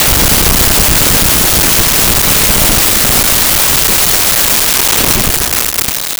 Explosion Large 3
Explosion Large_3.wav